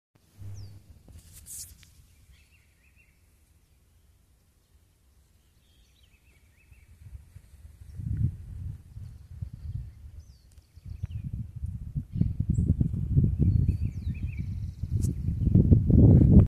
Juan Chiviro (Cyclarhis gujanensis)
Nombre en inglés: Rufous-browed Peppershrike
Condición: Silvestre
Certeza: Observada, Vocalización Grabada